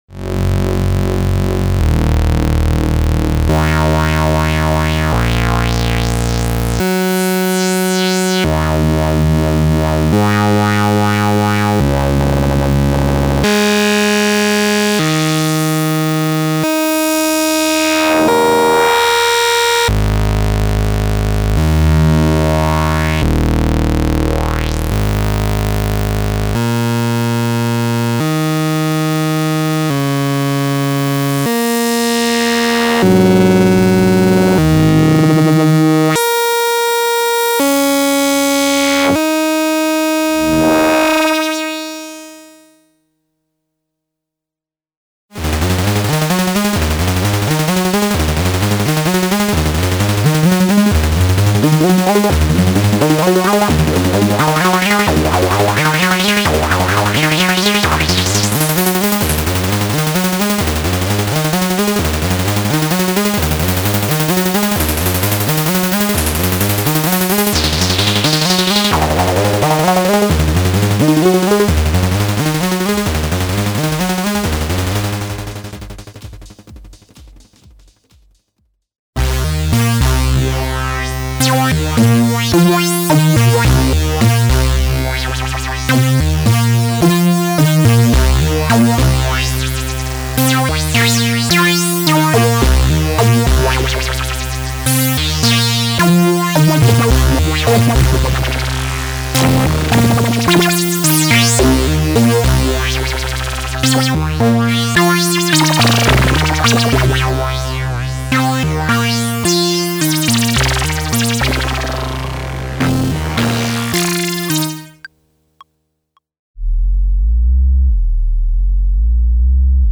editSOUND a monophonic synthesizer based on subtractive synthesis.
demo true Sh-101 filtered by minibrute